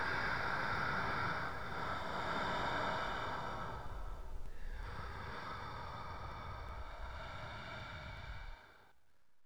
breath2.wav